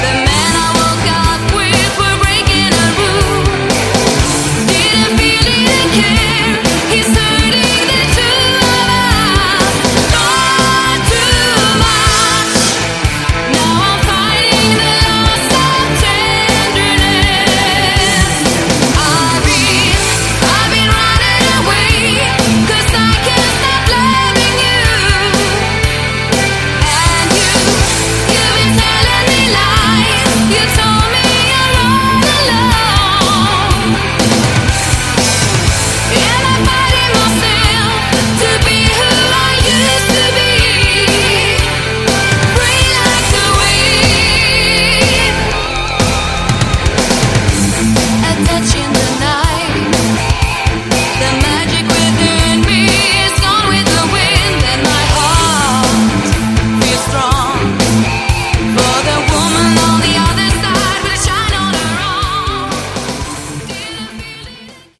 Category: Aor
vocals, background vocals
keyboards, background vocals
bass, acoustic guitar
drums
guitars